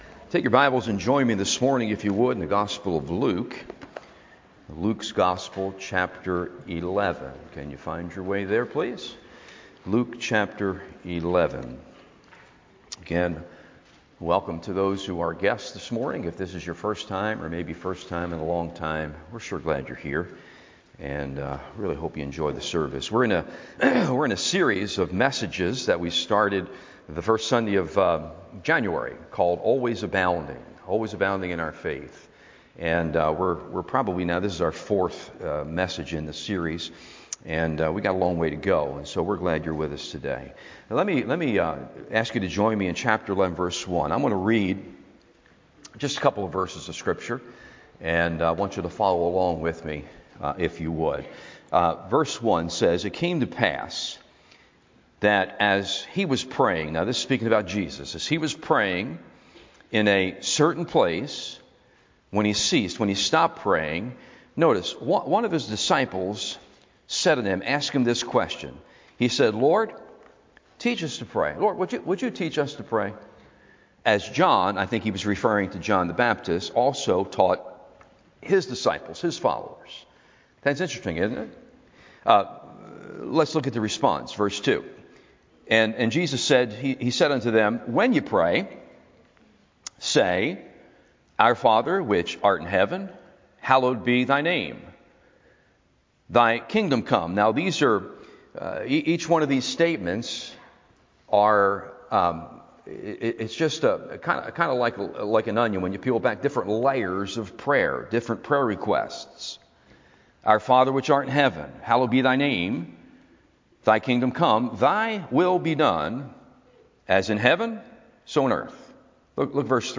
Always Abounding Series Sunday AM Service